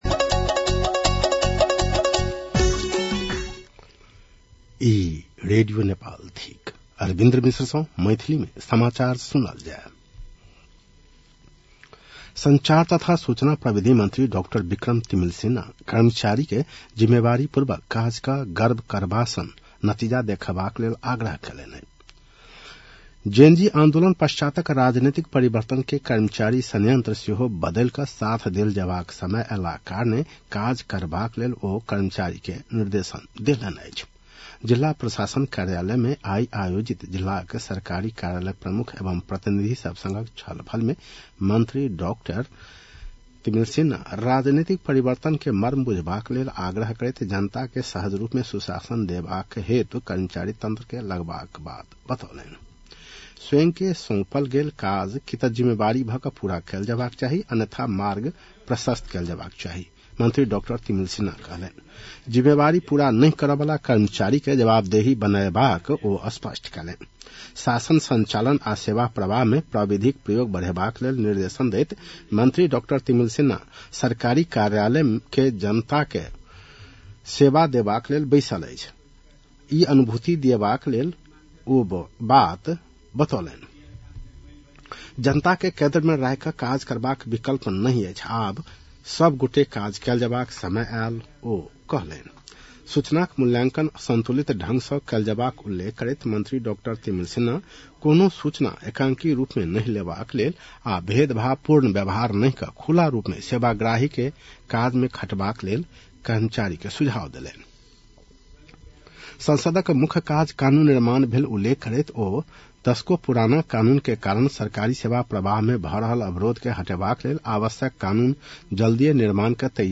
मैथिली भाषामा समाचार : ६ वैशाख , २०८३
6-pm-maithali-news-1-06.mp3